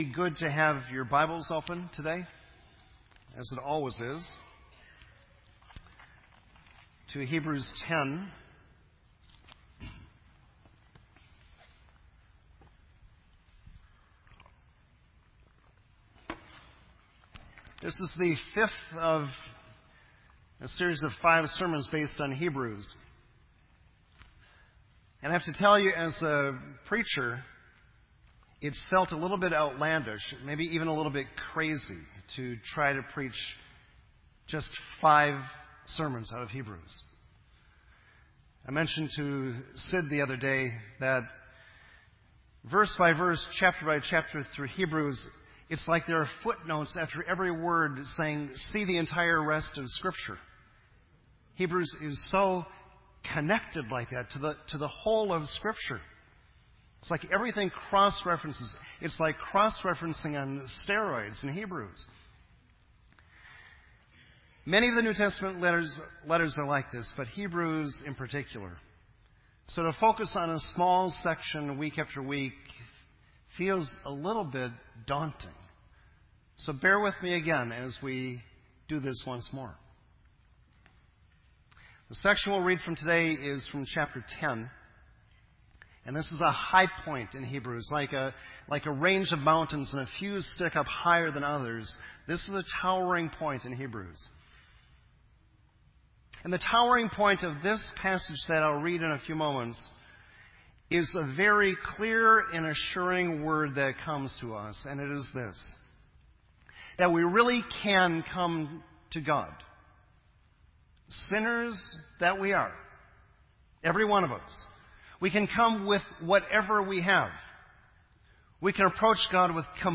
This entry was posted in Sermon Audio on November 23